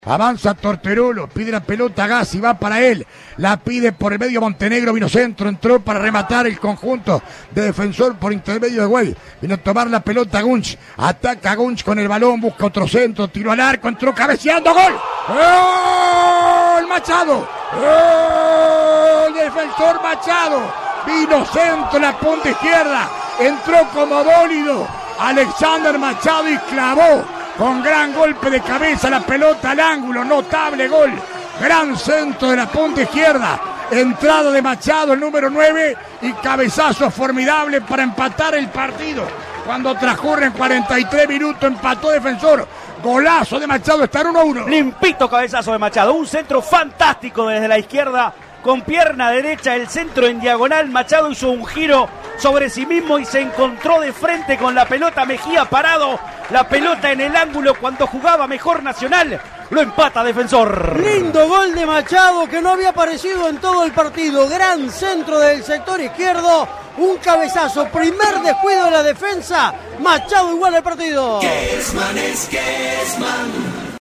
GOLES RELATADOS POR ALBERTO KESMAN